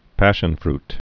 (păshən-frt)